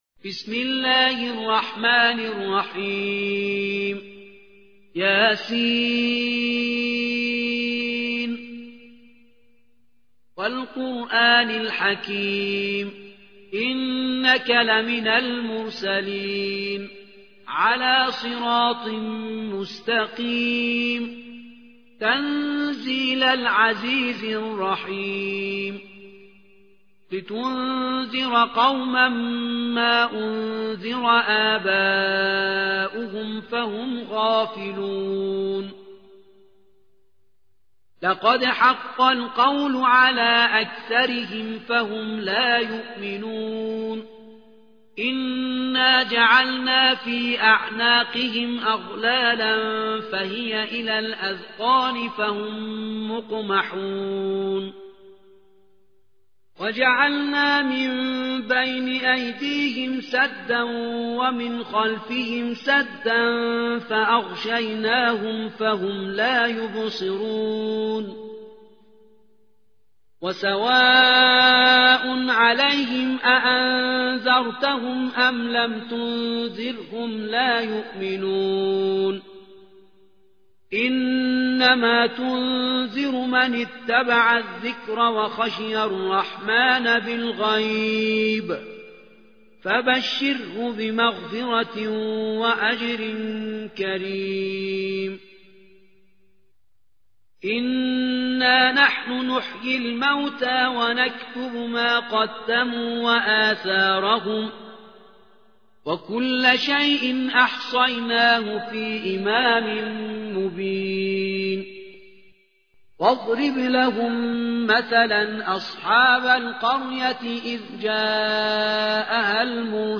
36. سورة يس / القارئ